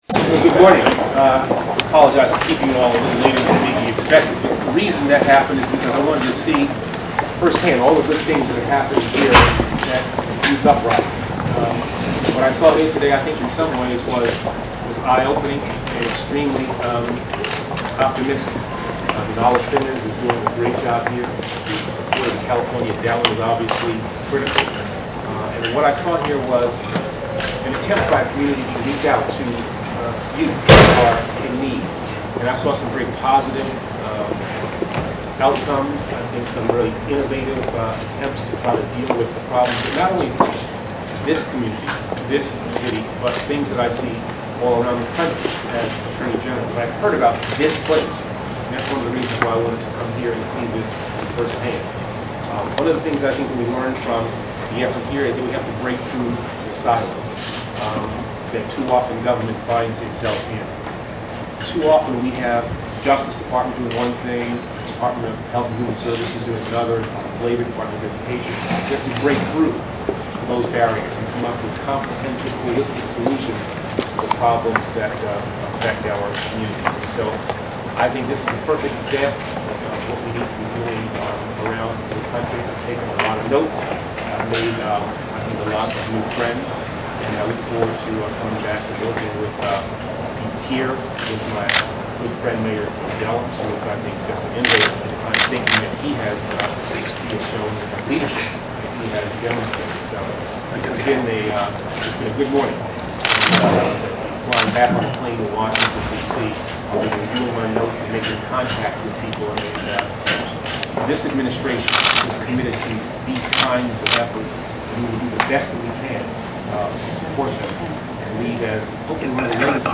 On Tuesday, May 11th, Eric Holder visited Youth Uprising, a youth empowering community center, located along MacArthur Blvd. in East Oakland. Holder was in town to discuss gang violence, and how the federal government may be able to assist local authorities in addressing this problem.